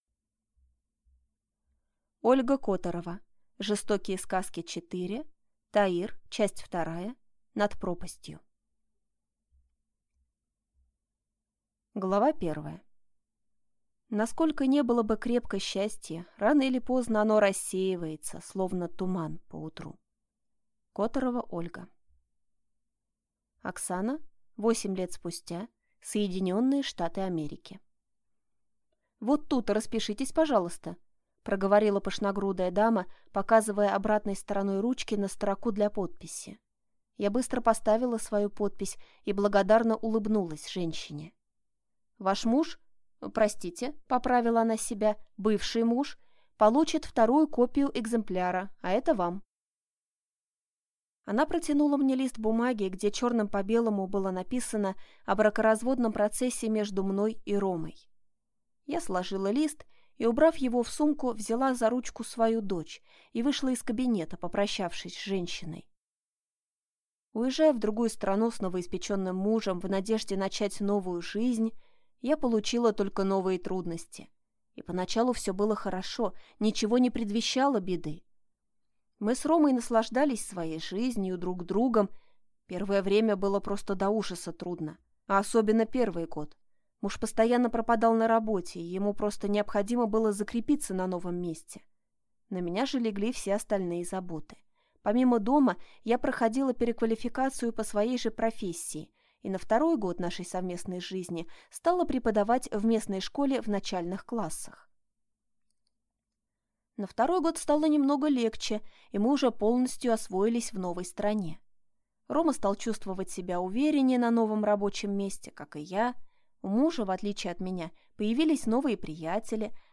Аудиокнига Таир 2. Над пропастью | Библиотека аудиокниг